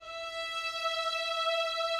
Added more instrument wavs
strings_064.wav